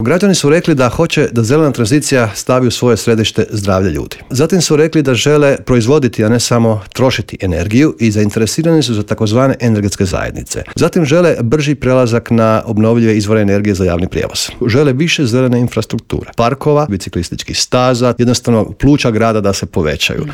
ZAGREB - Povodom međunarodne Konferencije "Zelena tranzicija pokreće europske gradove" predsjednik Gradske skupštine Grada Zagreba Joško Klisović gostovao je Intervjuu Media servisa.